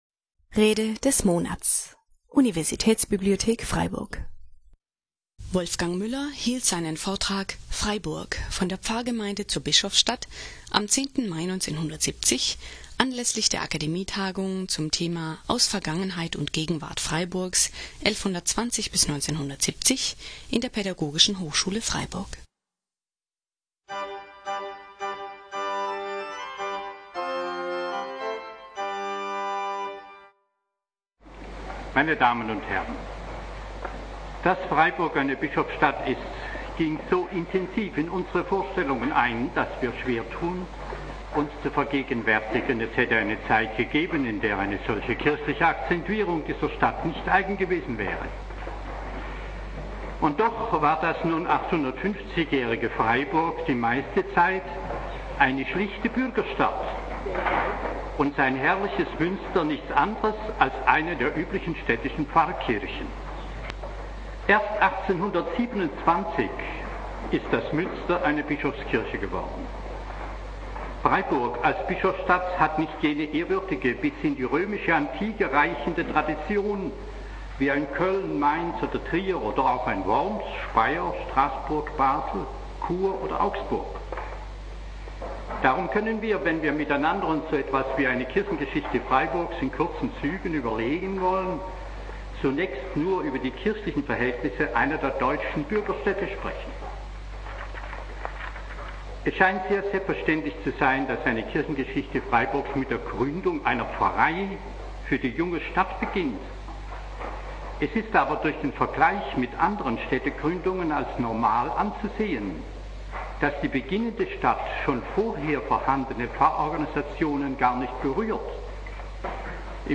Freiburg – von der Pfarrgemeinde zur Bischofsstadt (1970) 2 - Rede des Monats - Religion und Theologie - Religion und Theologie - Kategorien - Videoportal Universität Freiburg